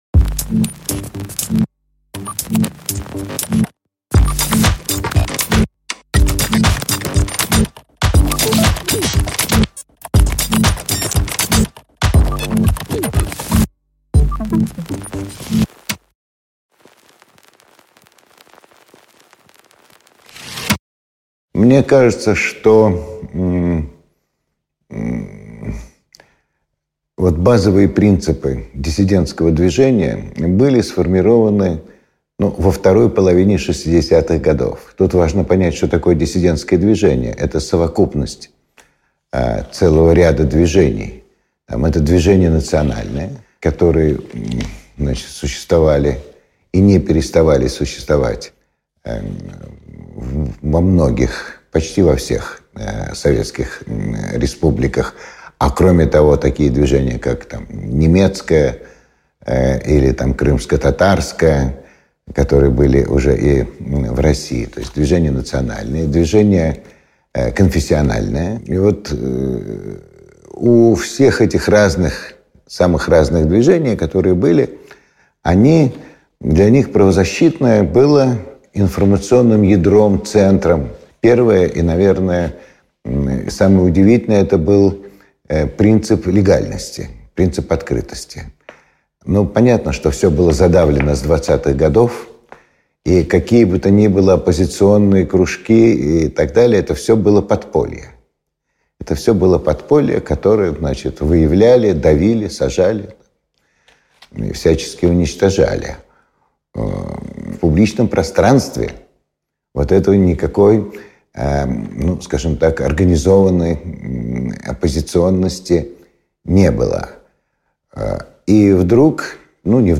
Аудиокнига Арсений Рогинский: «Понятие „прав человека“ уже было усвоено». Принципы дессидентского движение и его наследие | Библиотека аудиокниг